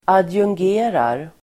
Ladda ner uttalet
Uttal: [adjungg'e:rar]